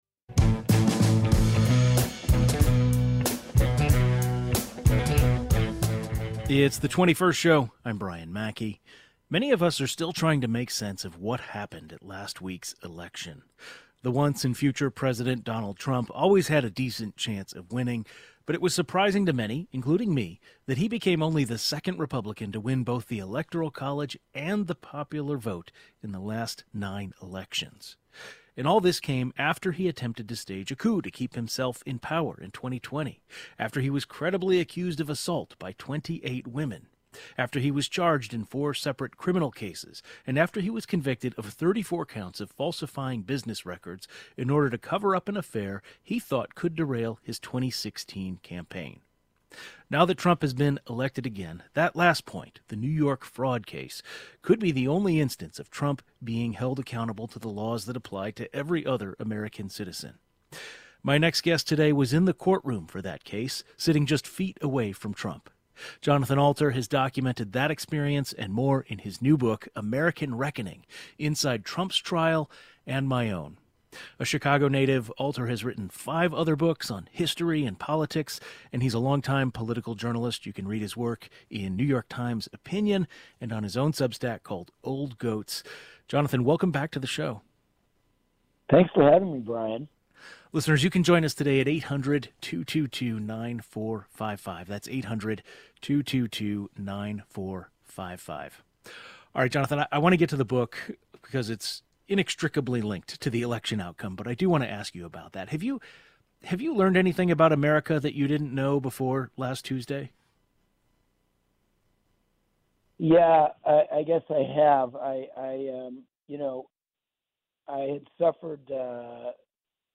An author, historian, and political journalist who has covered Trump's hush money trial shares his thoughts on that and what factors led to the Democrats failing to appeal to a majority of voters in this most recent election.